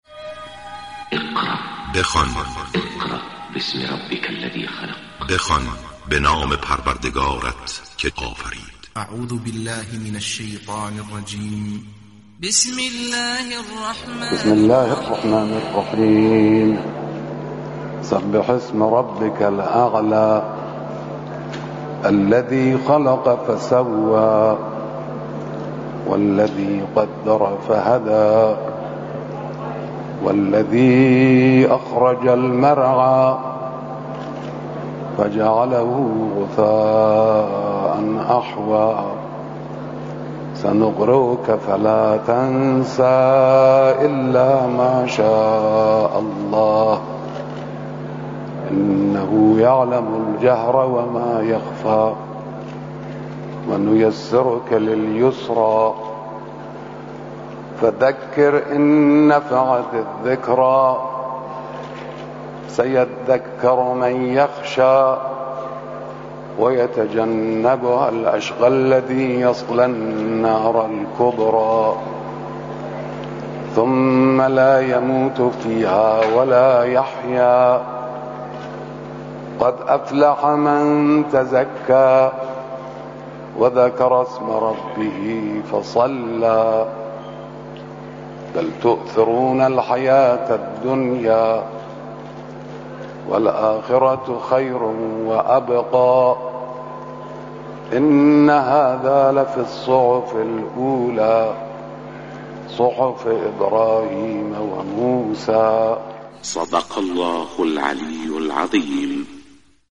قرائت قرآن با صدای آیت الله خامنه‌ای